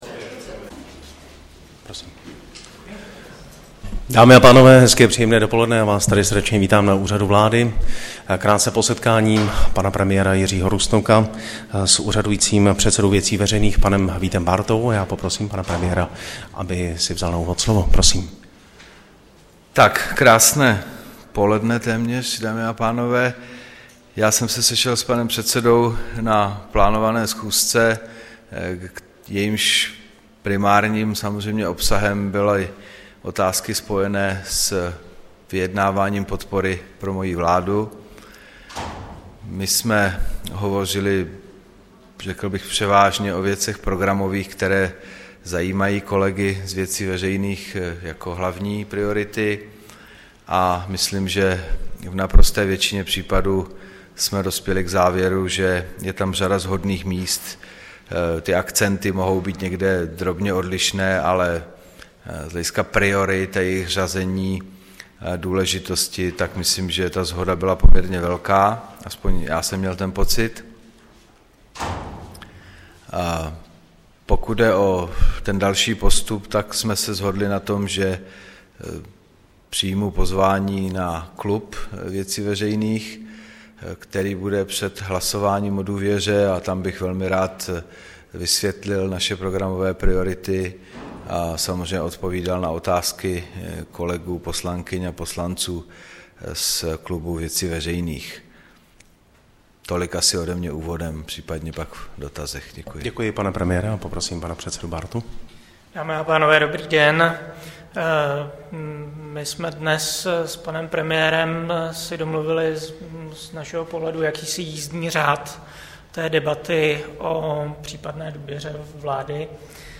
Tiskový brífink po setkání premiéra Jiřího Rusnoka s předsedou VV Vítem Bártou, 22. července 2013
Premiér Jiří Rusnok na tiskovém brífinku sdělil, že s předsedou VV Vítem Bártou našli shodu v řadě programových otázek. Vyjednávání o politické podpoře bude pokračovat setkáním na poslaneckém klubu VV před hlasováním o důvěře vládě.